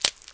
launch_dry_fire.wav